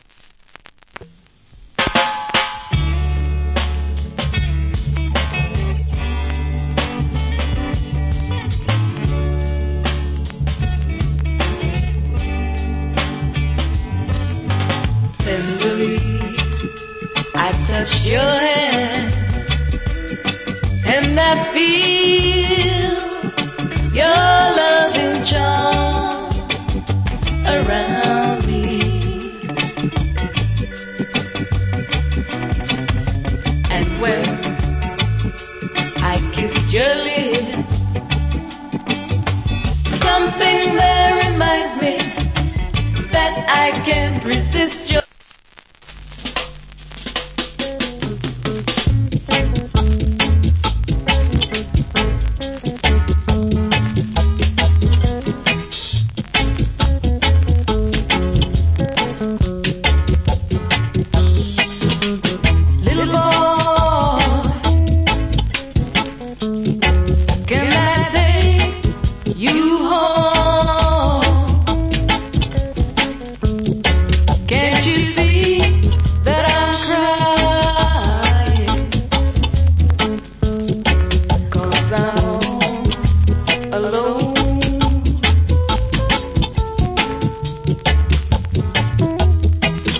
Good Female UK Reggae Vocal.